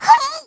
Worms speechbanks
Ow2.wav